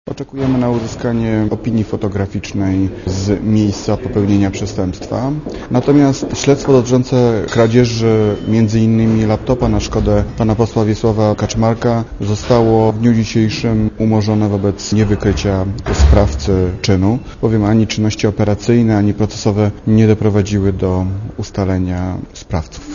Posłuchaj komentarza prokuratora